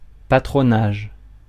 Prononciation
IPA: [pa.tʁo.naʒ]